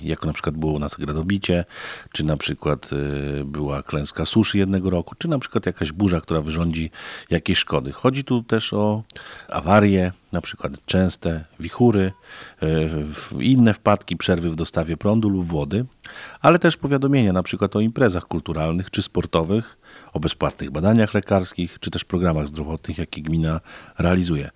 Wysyłamy tylko ważne informacje, żadnego spamu – zapewnia wójt